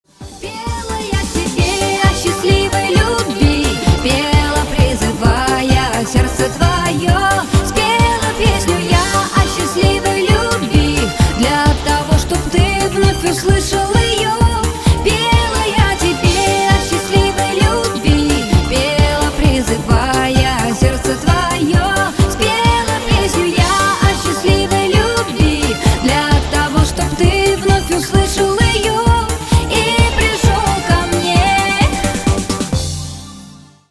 ретро